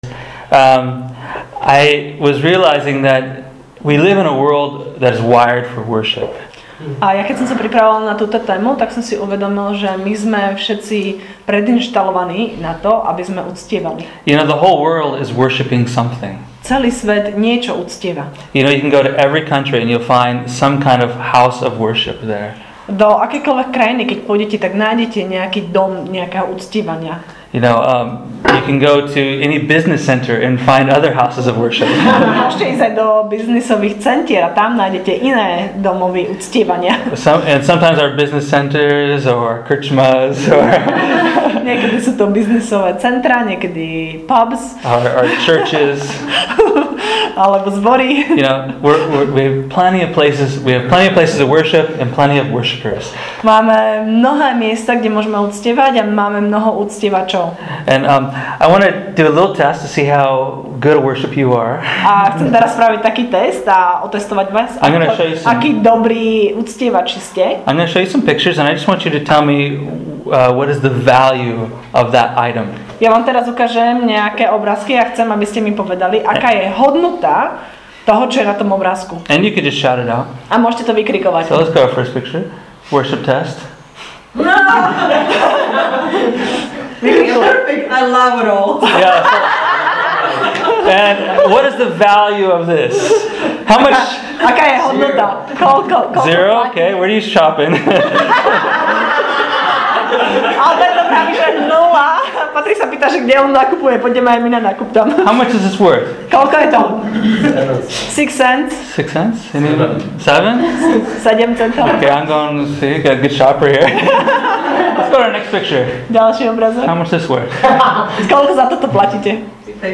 Enjoy this teaching entitled – “Treasure”